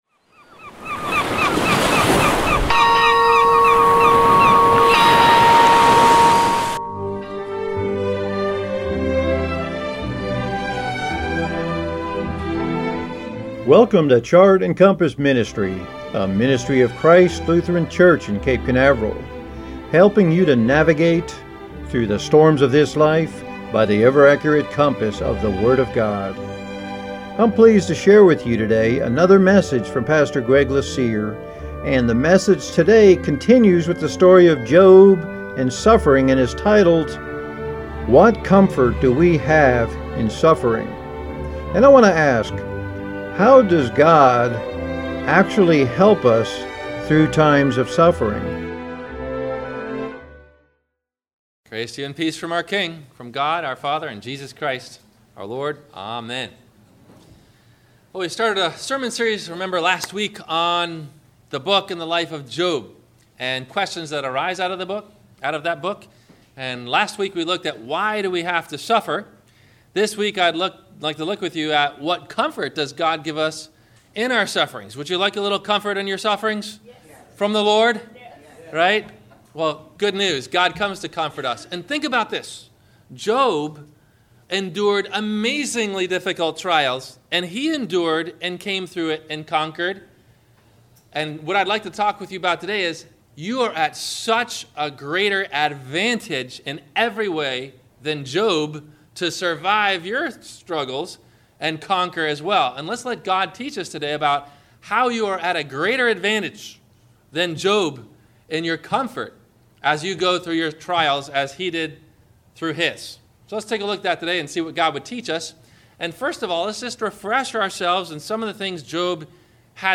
What Comfort Do We Have in Suffering? - WMIE Radio Sermon – April 25 2016 - Christ Lutheran Cape Canaveral